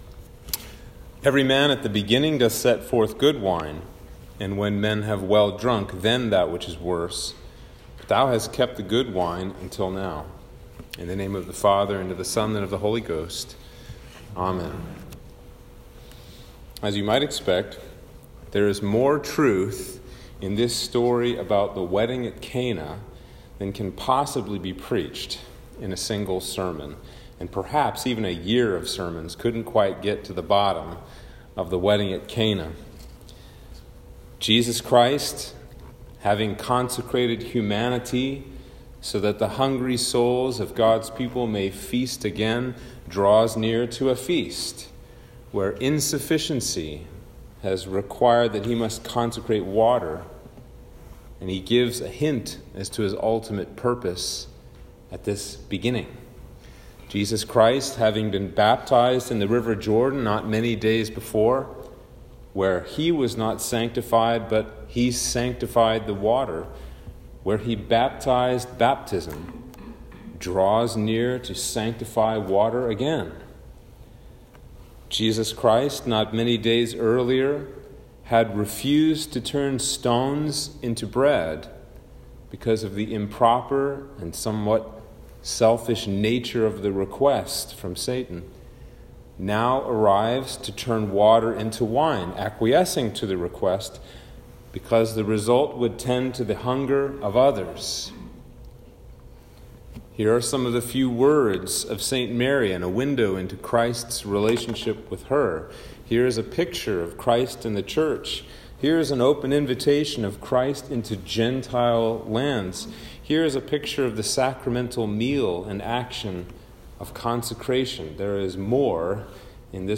Sermon for Epiphany 3 - 2022